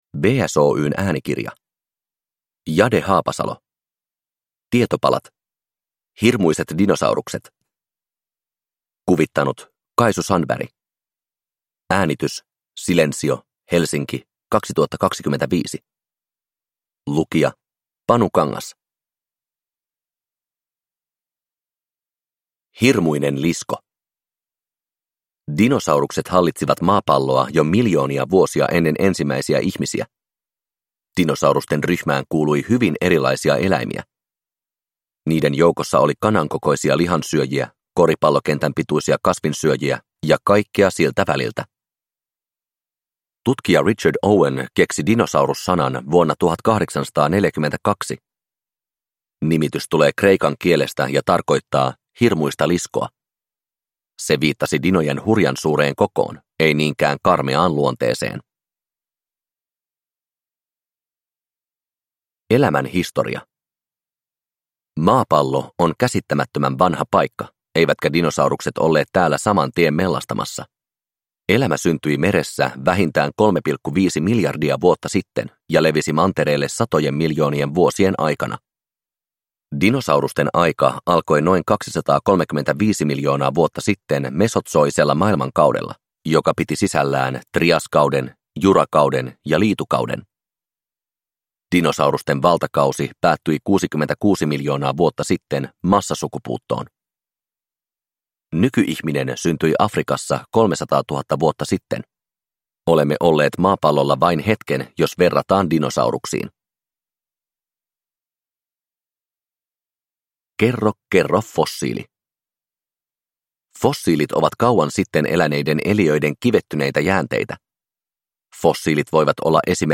Uppläsare